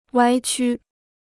歪曲 (wāi qū): mengaburkan; memutarbalikkan.